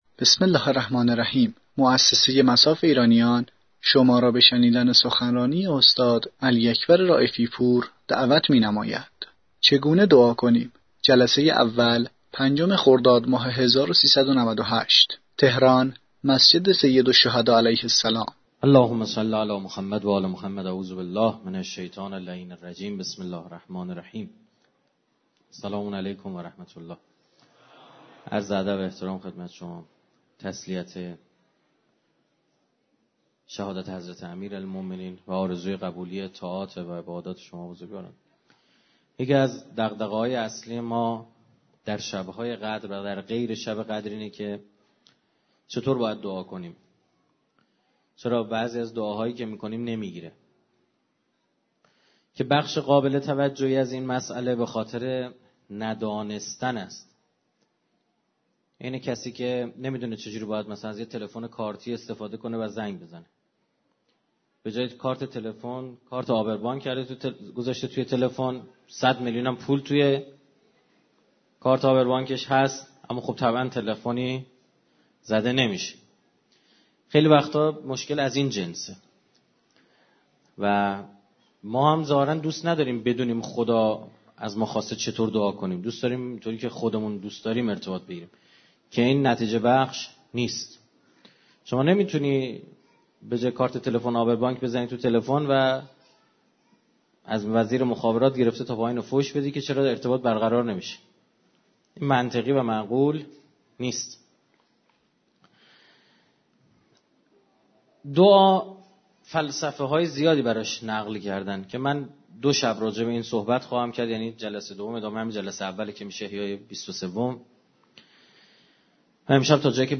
دانلود سخنرانی استاد رائفی پور